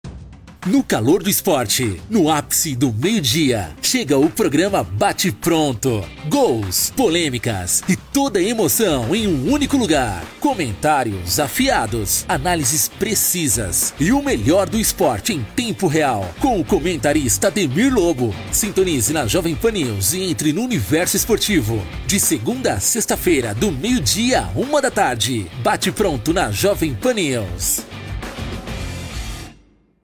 CHAMADA PARA JP E JP NEWS ESPORTES :
Impacto
Animada